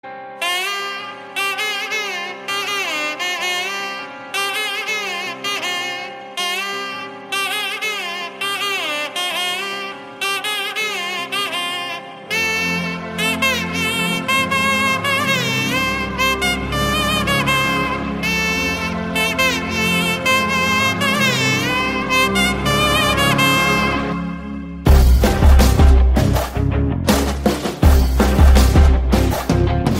powerful BGM and theme music